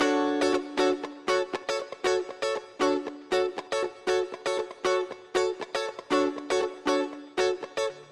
12 Guitar PT3.wav